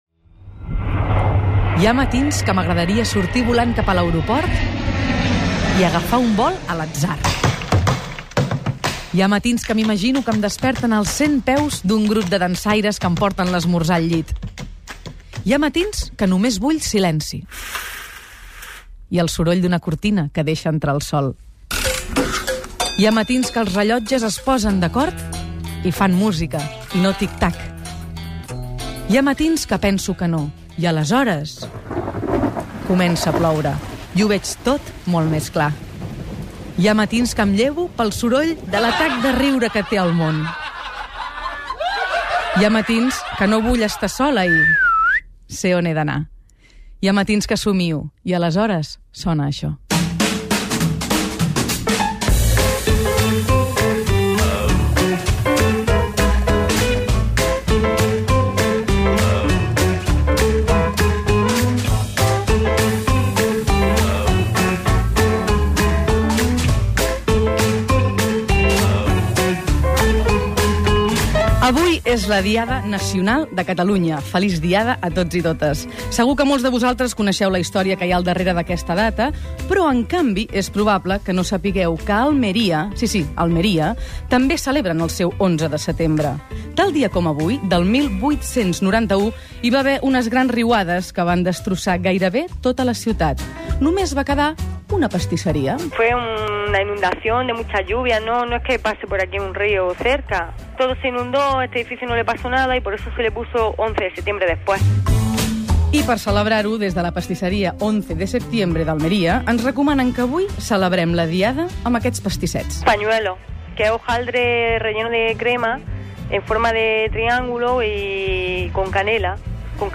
Comentari sobre els matins, música, el dia 11 de setembre a Almeria, indicatiu del programa, sumari de continguts
Entreteniment